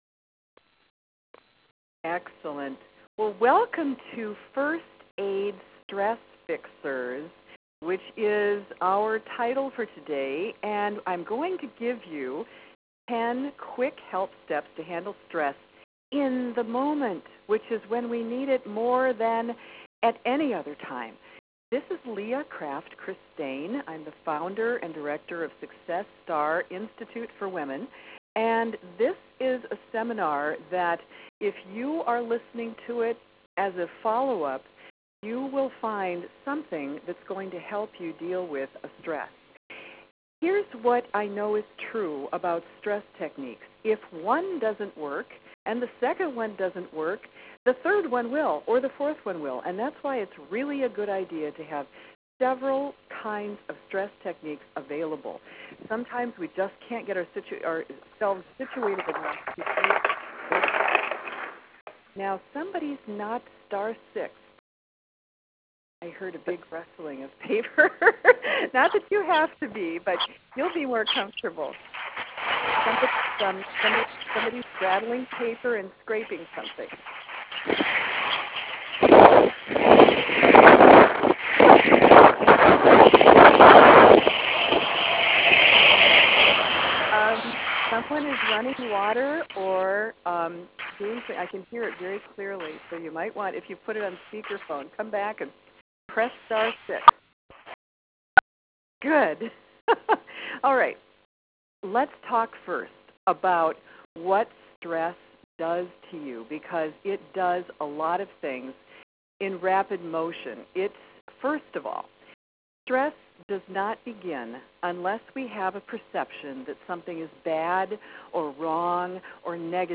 Stress Management Tele-Seminar Audio & Handout